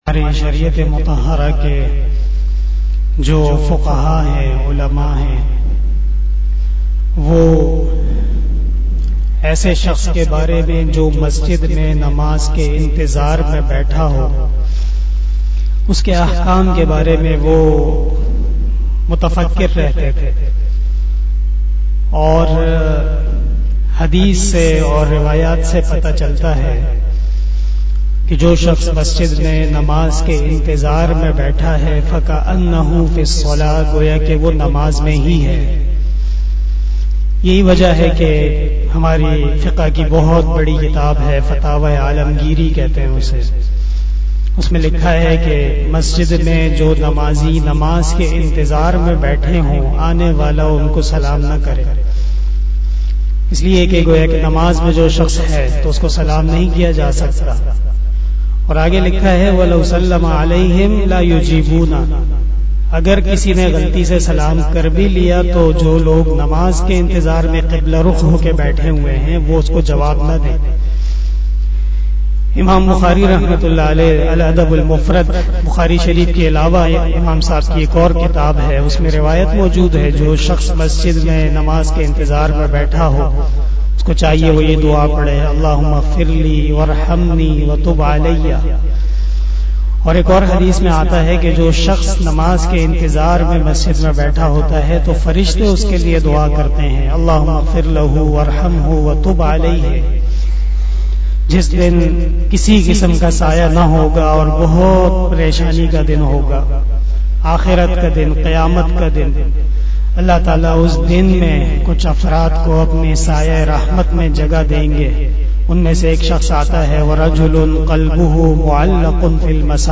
071 AfterAsar Namaz Bayan 15 October 2021 (08 Rabi ul Awwal 1443HJ) Friday